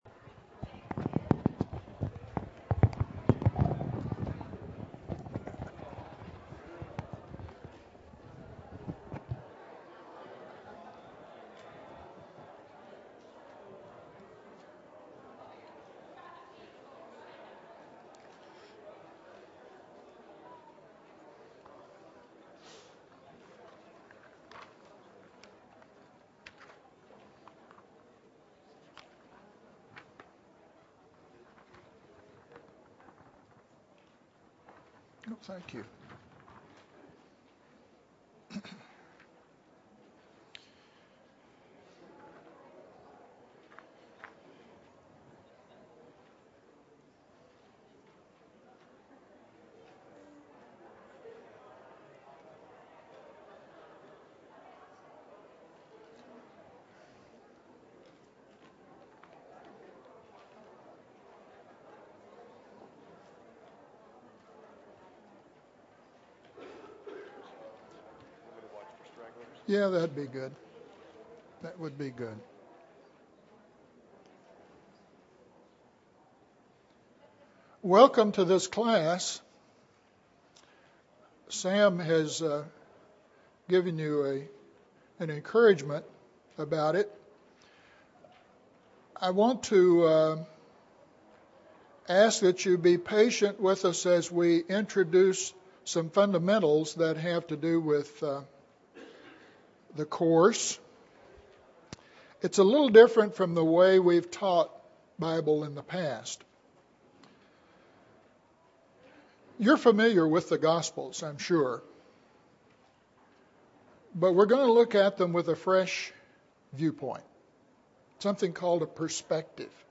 Introduction to the Course: The Human Jesus; Easter& Western Thought; Readings (1 of 13) – Bible Lesson Recording
Sunday AM Bible Class